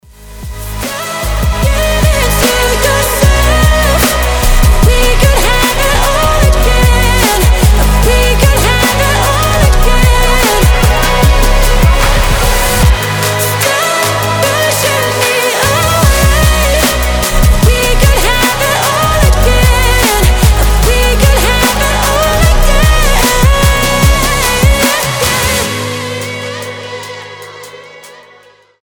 громкие
Electronic
басы
красивый вокал
драм энд бейс